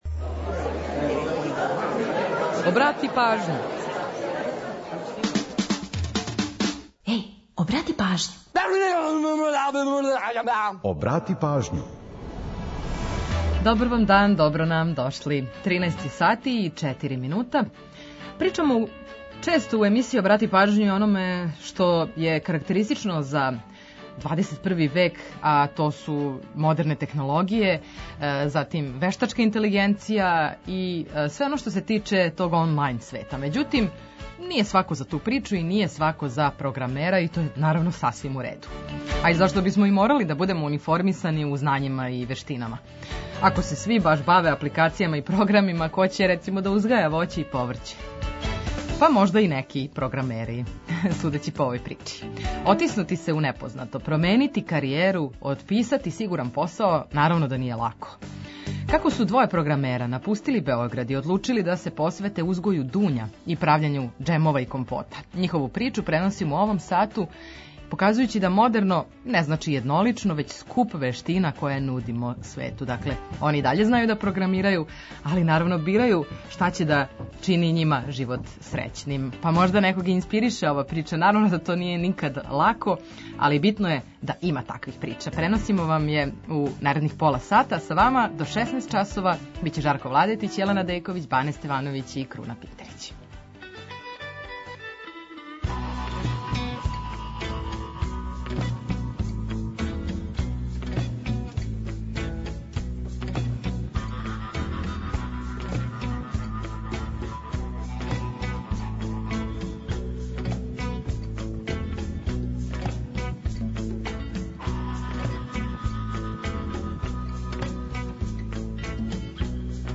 Чућете „Приче о песмама”, пола сата музике из Србије и региона, а упозоравамо и на евентуалне саобраћајне гужве.